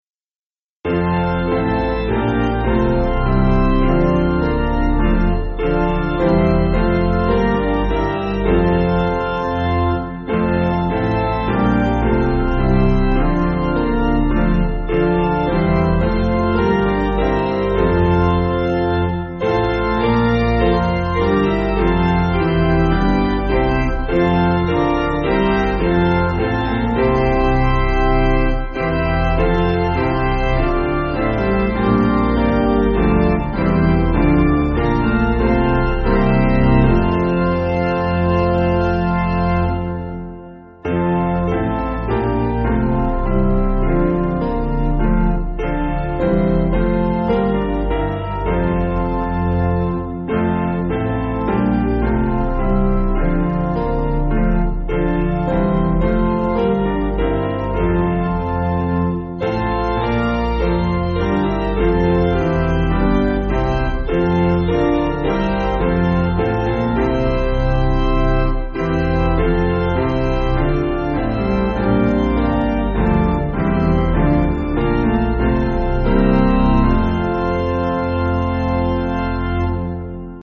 Basic Piano & Organ